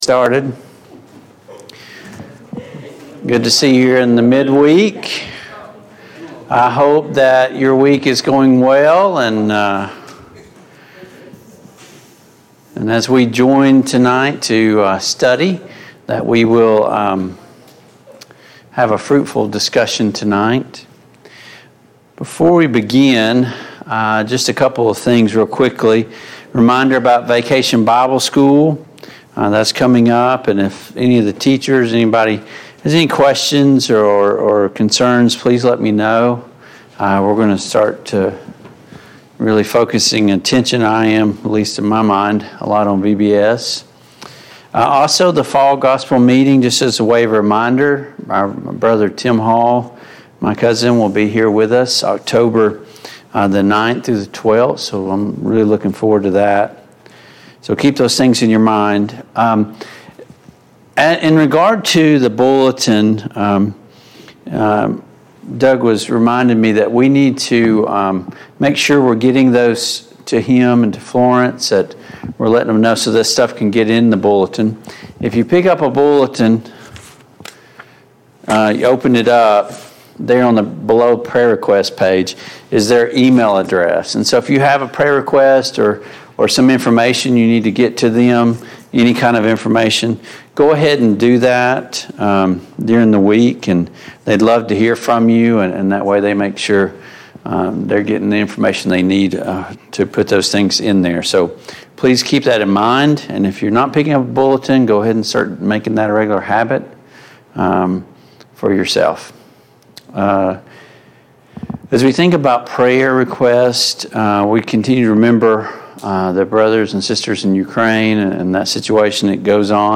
The Kings of Israel Service Type: Mid-Week Bible Study Download Files Notes Topics: The Divided Kingdom « What motivates and drives our spiritual walk? 6.